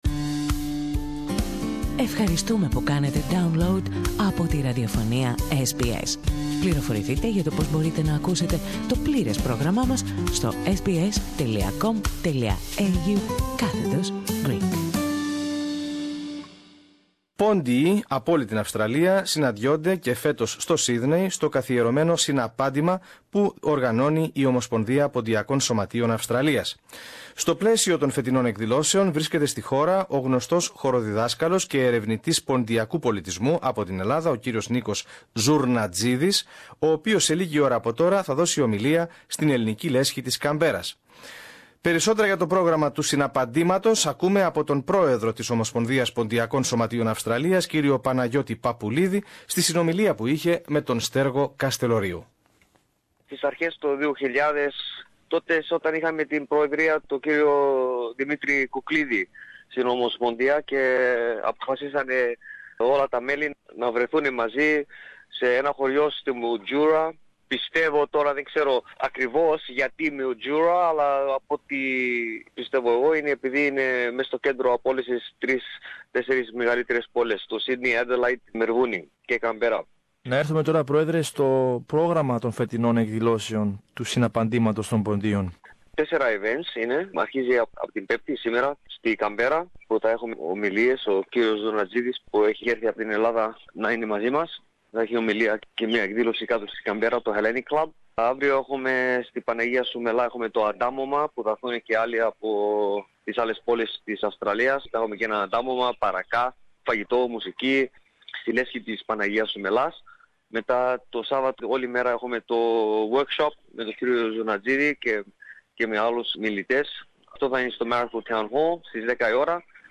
στην συνομιλία